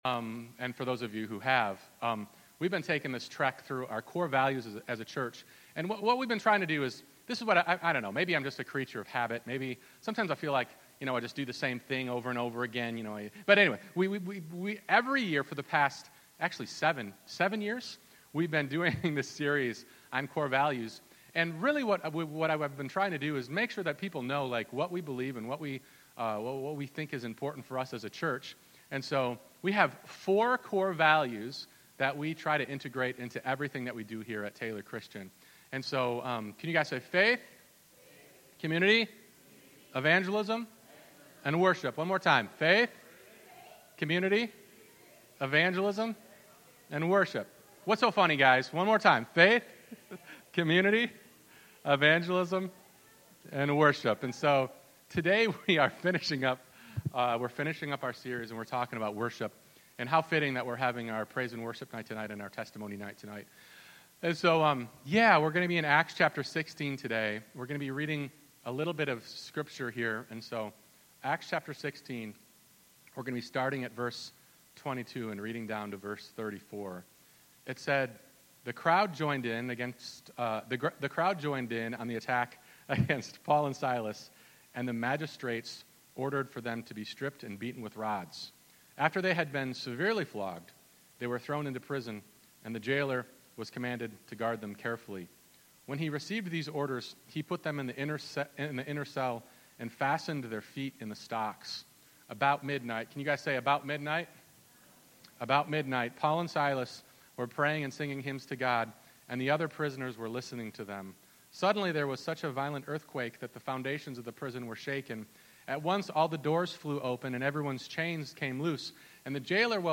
1 Sunday Service 35:24